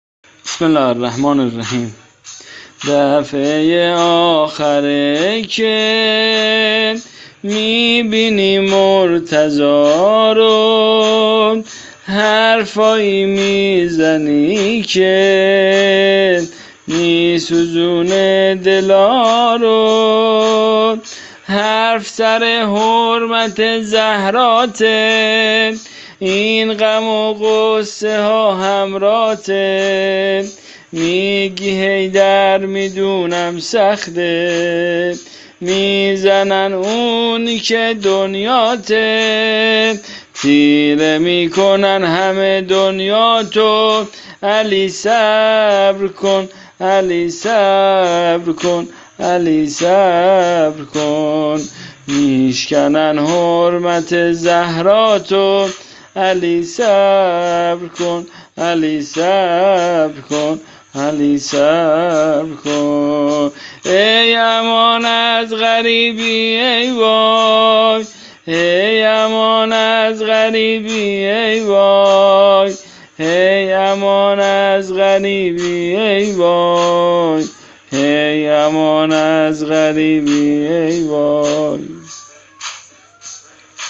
زمینه شهادت رسول الله صل الله علیه و آله وسلم